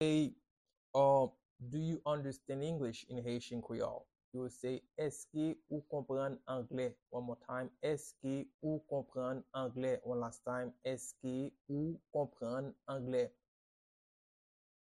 Pronunciation and Transcript:
Do-you-understand-English-in-Haitian-Creole-–-Eske-ou-konprann-angle-pronunciation-by-a-Haitian-teacher.mp3